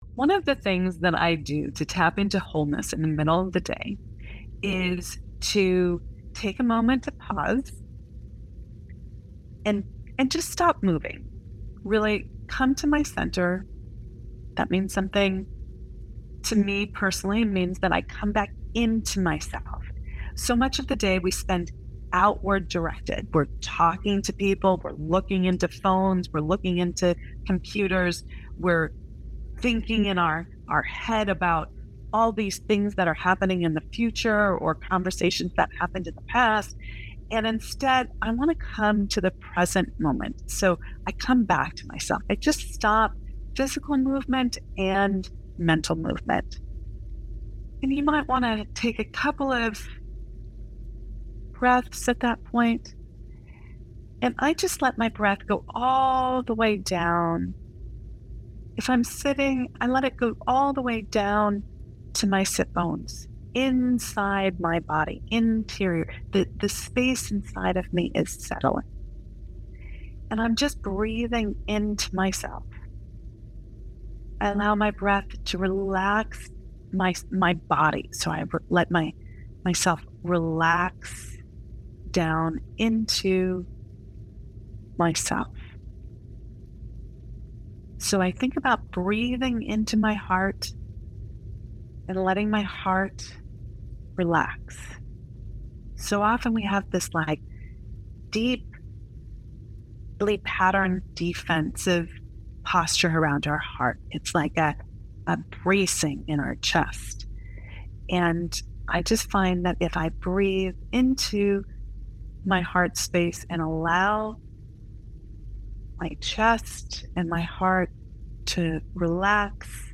Podcast-2-_-Meditation.mp3